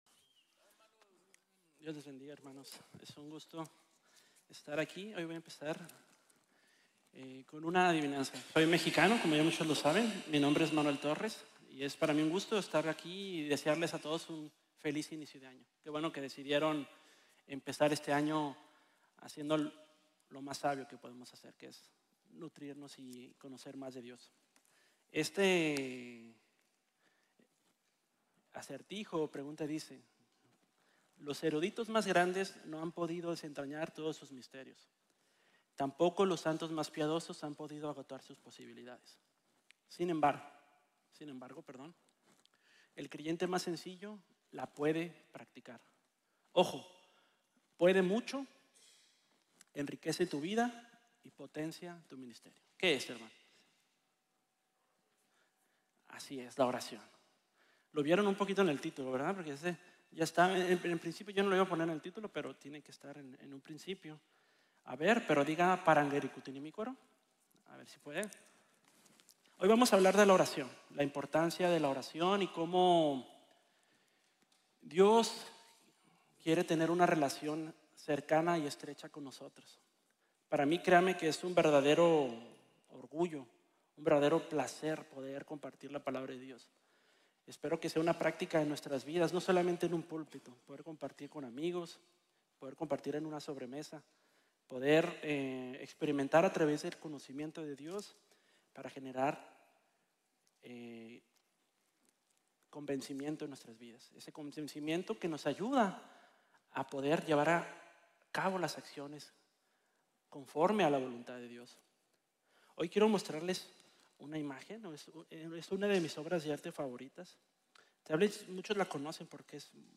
Mi oración por mi iglesia | Sermon | Grace Bible Church